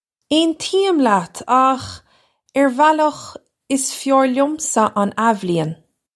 Pronunciation for how to say
Ayn-tee-im lyat akh, urr valukh, iss fyar lyumsa un Ah-vlee-in.
This is an approximate phonetic pronunciation of the phrase.